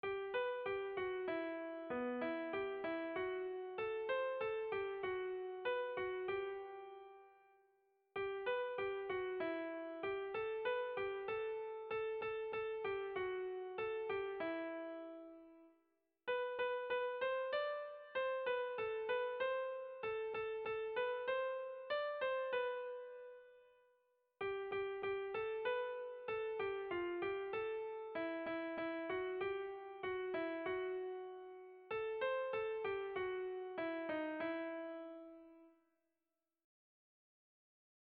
Sentimenduzkoa
Zortziko handia (hg) / Lau puntuko handia (ip)